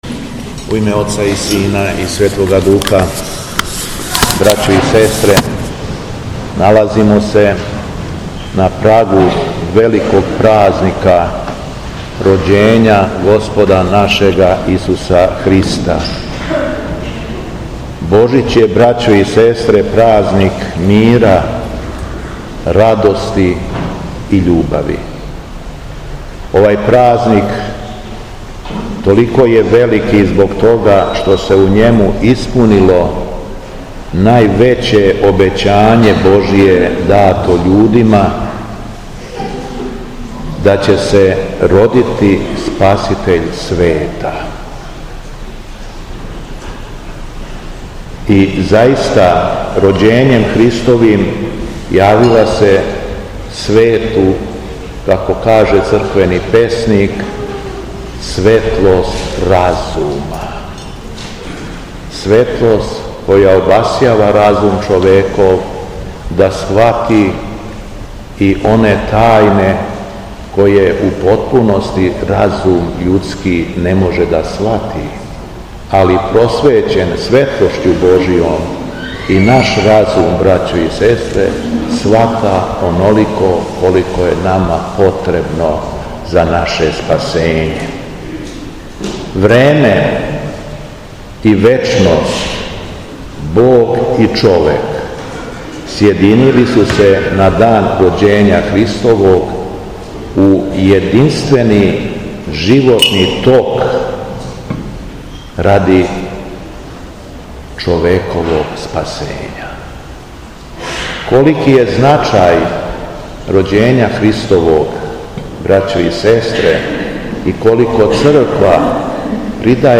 Беседа Његовог Високопреосвештенства Митрополита шумадијског г. Јована
У својој очинској беседи после прочитаног јеванђелског зачала Митрополит Јован је рекао: